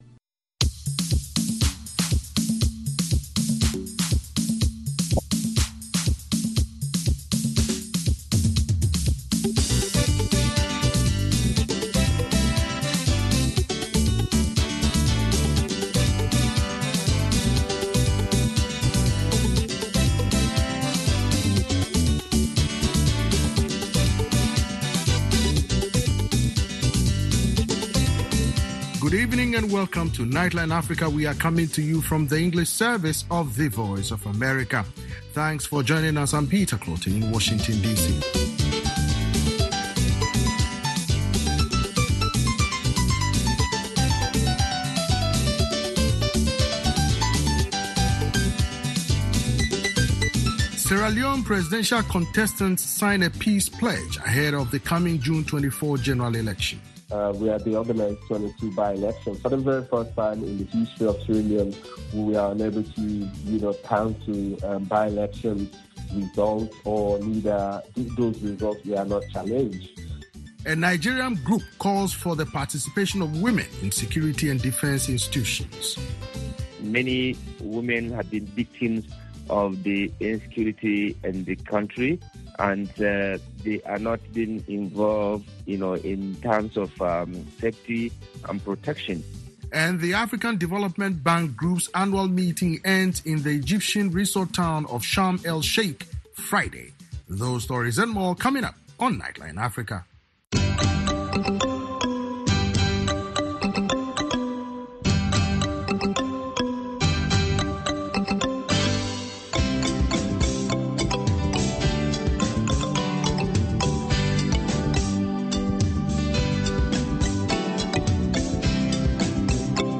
On Nightline Africa: Sierra Leonian political analysts applaud incumbent President Julius Maada Bio, his rival Samura Kamara, and other presidential candidates for signing a peace pledge to ensure that the June 24 elections are democratic with no violence. Plus, VOA has an exclusive sit down with Senegal’s former prime minister Idrissa Seck who discusses his presidential aspirations.